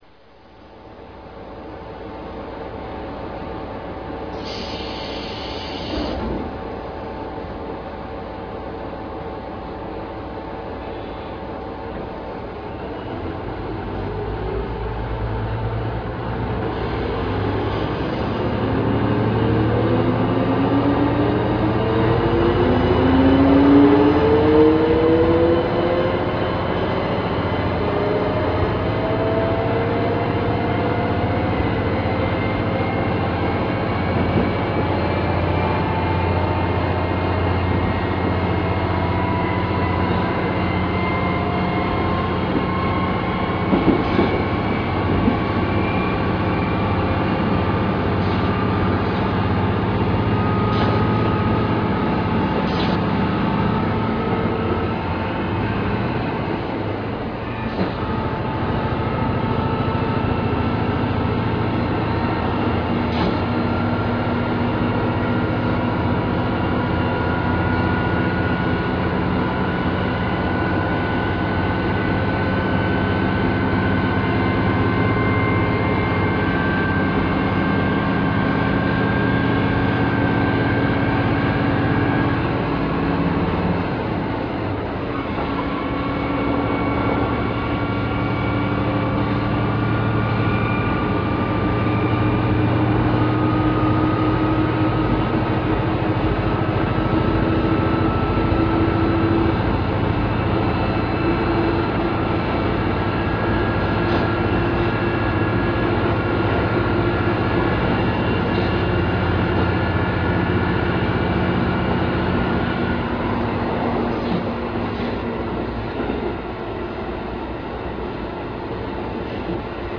走行音[k11a.ra/431KB]
エンジン：C-DMF14HZA(330PS)×1
変速機：C-DW15(直結2段式)